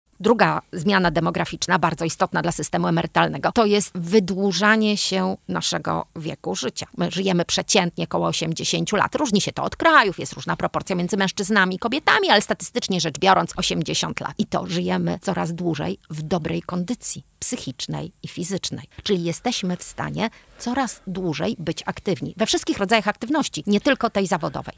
[PORANNA ROZMOWA] Czy wiek emerytalny zostanie podwyższony?